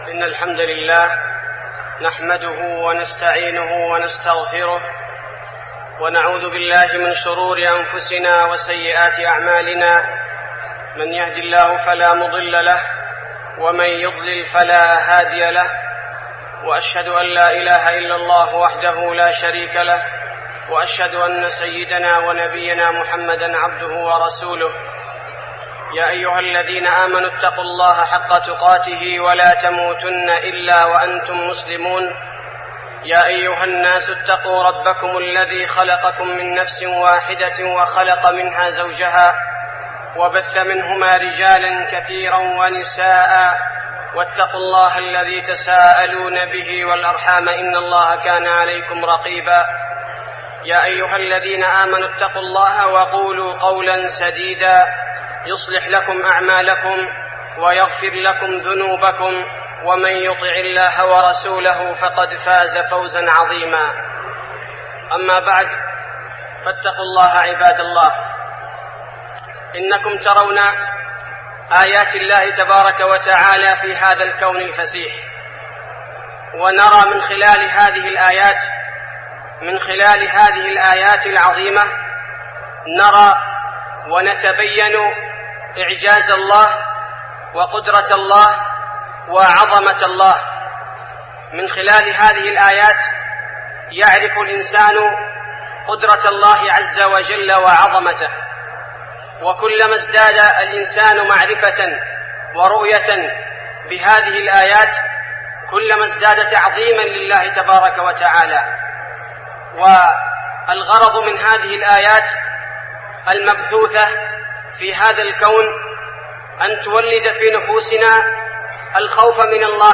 خطبة الخسوف المدينة - الشيخ عبدالباري الثبيتي - الموقع الرسمي لرئاسة الشؤون الدينية بالمسجد النبوي والمسجد الحرام
تاريخ النشر ١٥ رجب ١٤١٧ هـ المكان: المسجد النبوي الشيخ: فضيلة الشيخ عبدالباري الثبيتي فضيلة الشيخ عبدالباري الثبيتي خطبة الخسوف المدينة - الشيخ عبدالباري الثبيتي